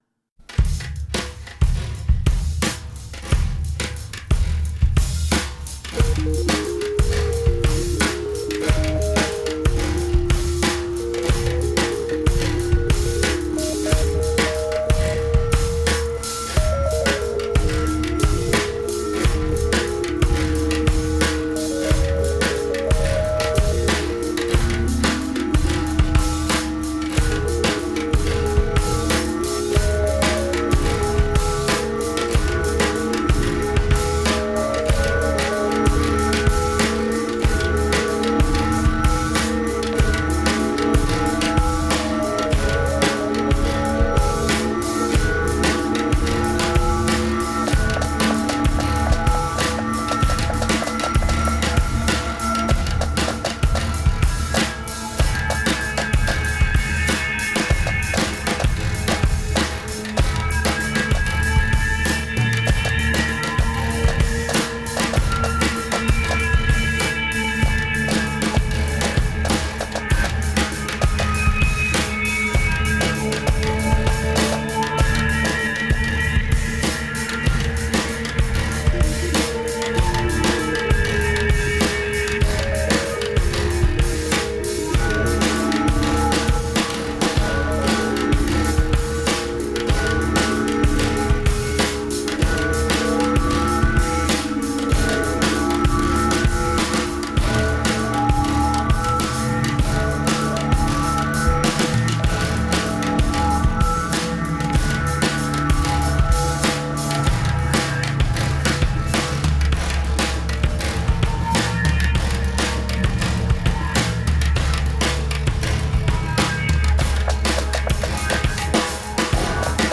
imported Industrial from Chicago
Perhaps best described as simply “experimental,”
bass
electronics
drums